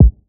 {Kick} first take (1).wav